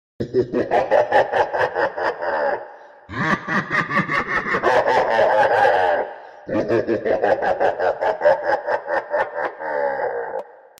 دانلود صدای خنده شیطانی 2 از ساعد نیوز با لینک مستقیم و کیفیت بالا
جلوه های صوتی